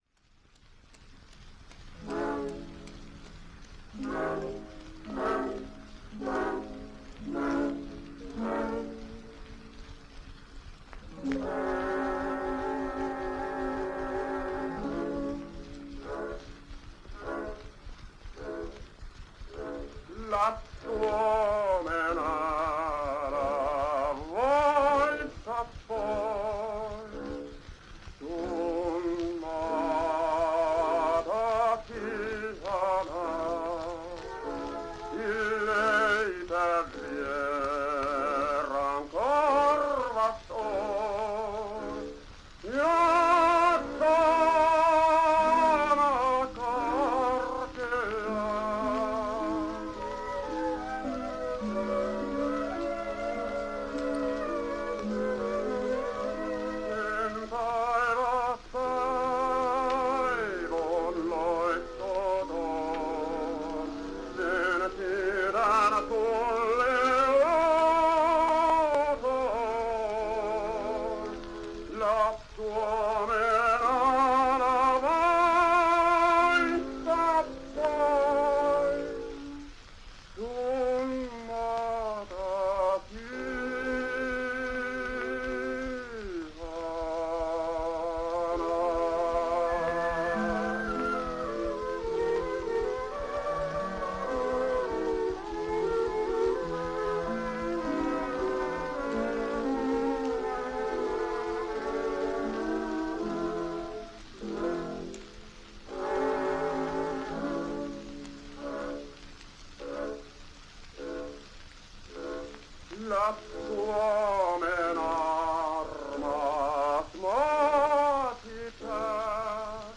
Tenor solo with orchestra accompaniment.
Songs, Finnish.
Songs (High voice) with orchestra.
Popular music—1911-1920.